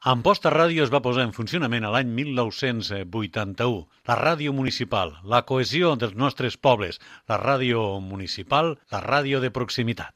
Enregistrat amb motiu del Dia Mundial de la Ràdio 2021.
FM